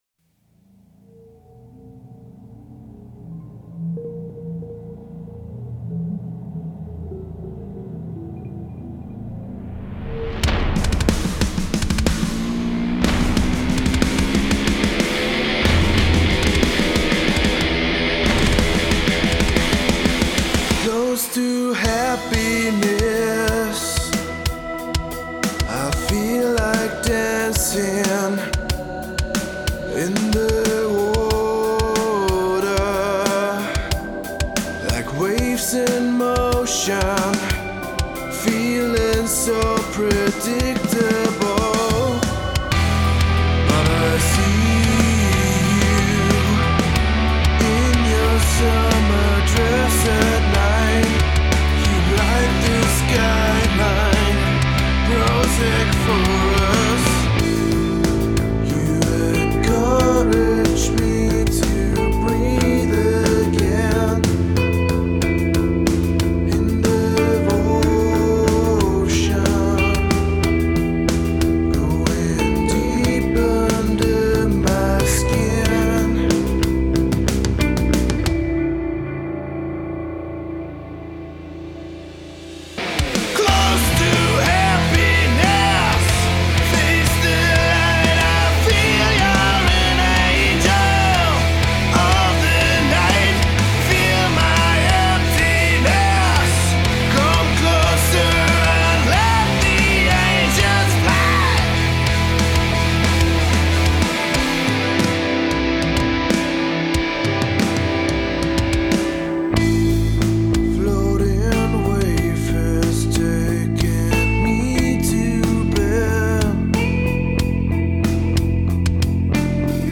starker Song! Mir haben jetzt eigentlich nur im Refrain Dopplungen bei den vox gefehlt...